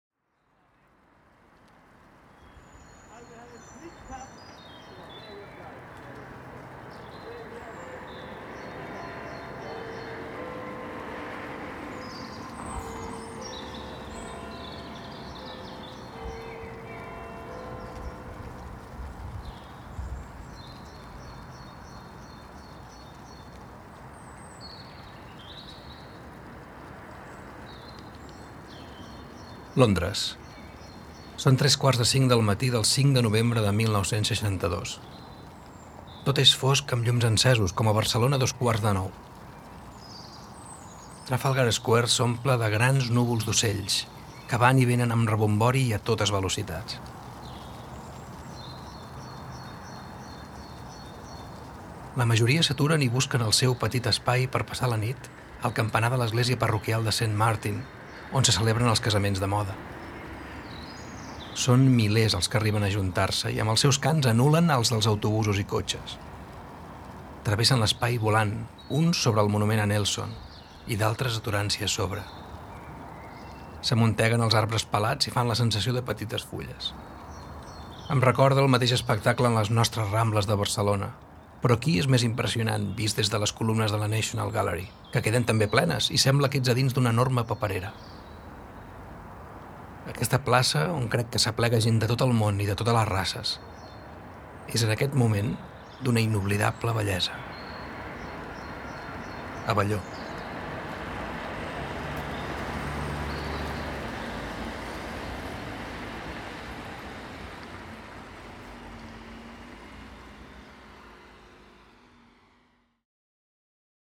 We have recreated through voice and sound three fragments of letters that Joan Abelló wrote during his travels and that transport us to that place and at that moment.